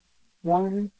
Reconstruction from classifier (with diffusion enhancement)